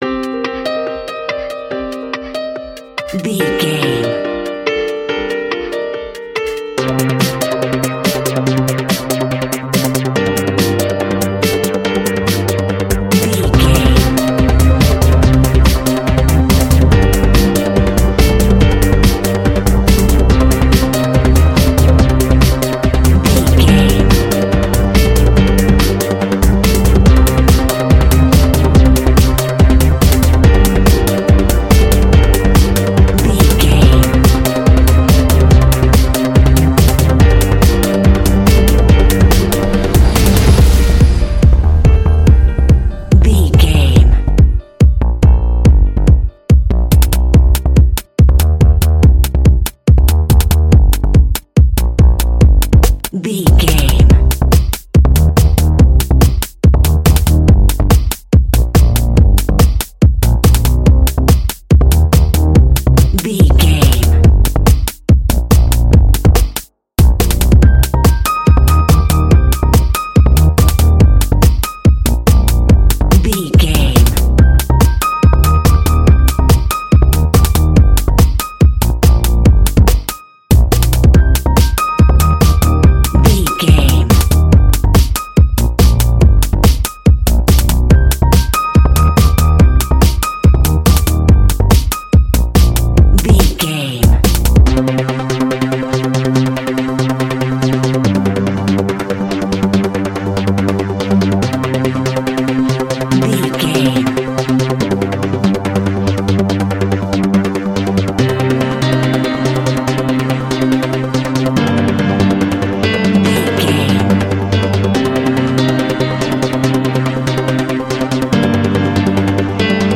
Wired up Trance.
Aeolian/Minor
groovy
peaceful
meditative
smooth
drum machine
synthesiser
piano
electronic
synth leads
synth bass